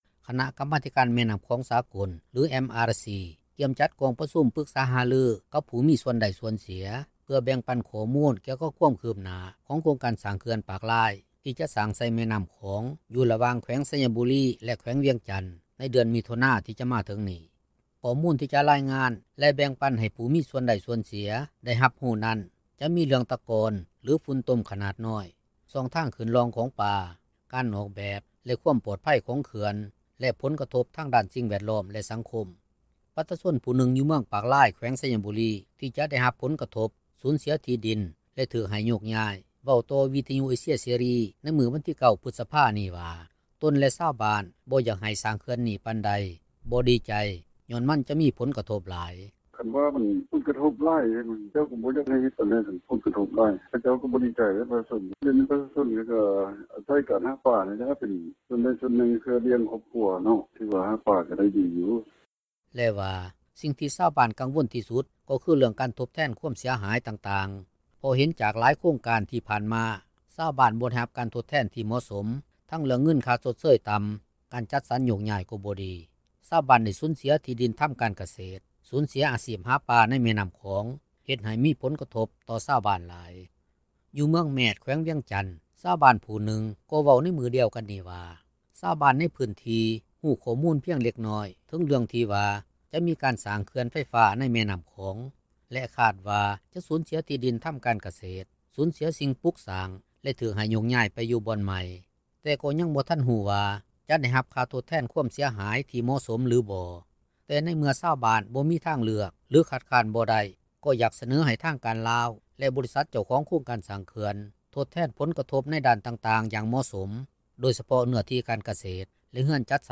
ປະຊາຊົນຜູ້ໜຶ່ງ ຢູ່ເມືອງປາກລາຍ ແຂວງໄຊຍະບູລີ ທີ່ຈະໄດ້ຮັບຜົນກະທົບ-ສູນເສຍທີ່ດິນ ແລະຖືກໃຫ້ໂຍກຍ້າຍ ເວົ້າຕໍ່ວິທຍຸເອເຊັຽເສຣີ ໃນມື້ວັນທີ 9 ພຶດສະ ພານີ້ວ່າ ຕົນ ແລະຊາວບ້ານ ບໍ່ຢາກໃຫ້ສ້າງເຂື່ອນນີ້ປານໃດ ບໍ່ດີໃຈ ຍ້ອນມັນຈະມີຜົນກະທົບຫລາຍ.